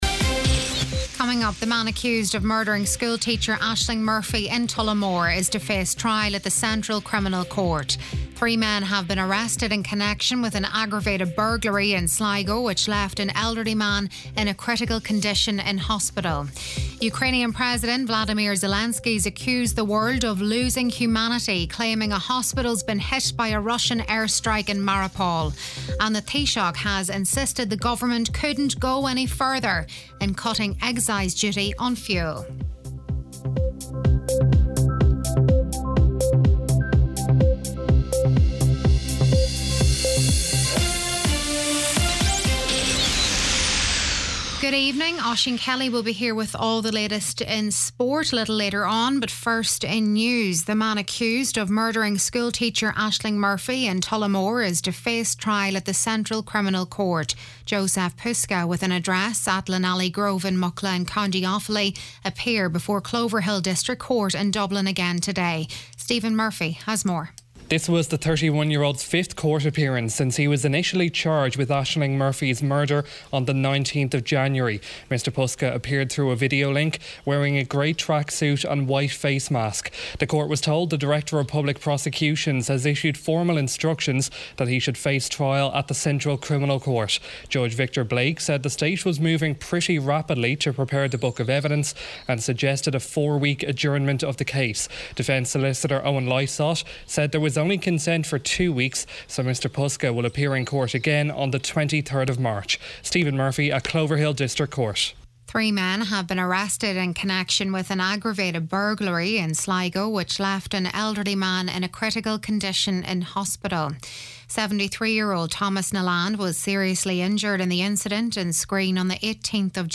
Listen back to main evening news, sport & obituaries